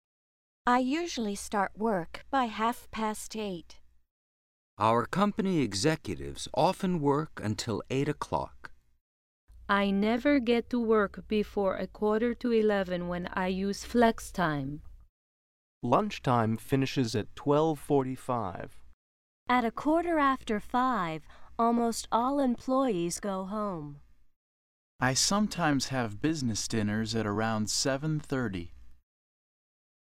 Listen to the audio and take notes of the hours you hear these people say.